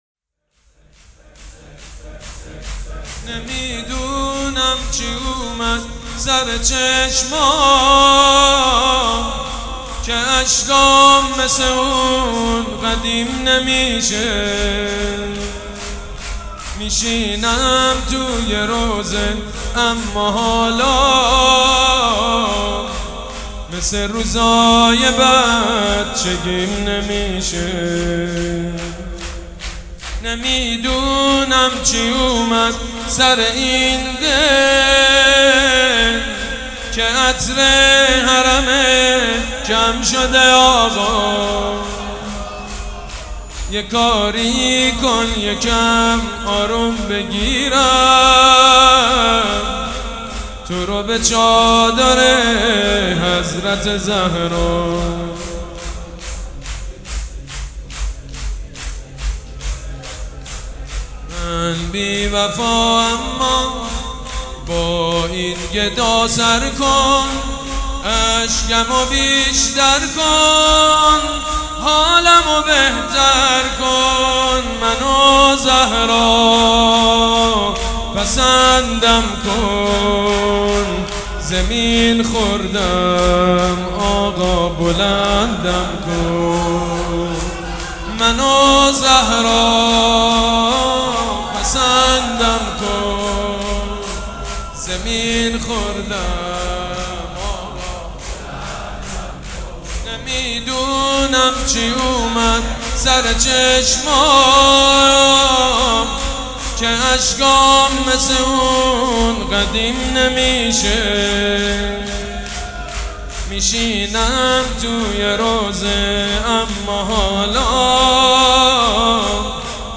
شور
مداح
مراسم عزاداری شب سوم